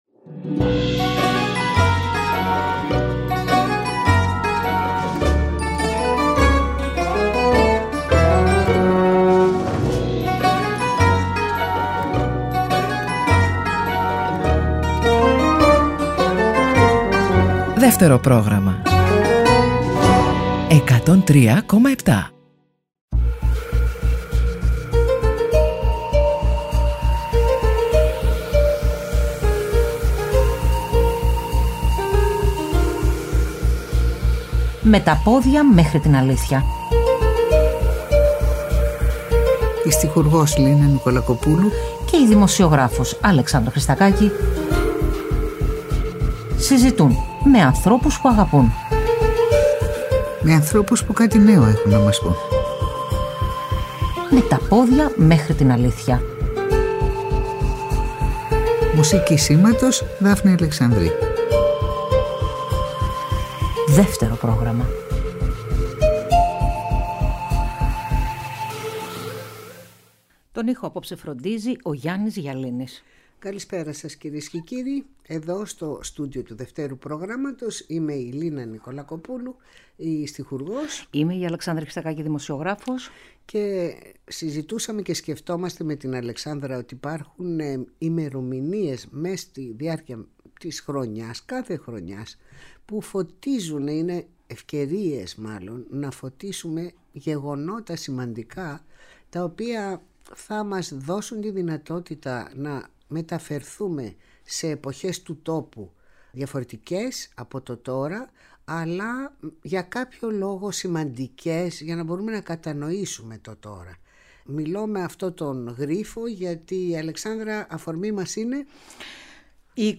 Ακούγεται ο ποιητής Κωστής Παλαμάς να απαγγέλλει μέρος του ποιήματός του “Ο Τάφος” που έγραψε μετά τον πρόωρο θάνατο του 5χρονου γιου του καθώς και ηχητικά ντοκουμέντα από μαρτυρίες λόγιων νεαρών της εποχής που κράτησαν το φέρετρο και συμμετείχαν στην κηδεία του Εθνικού μας ποιητή.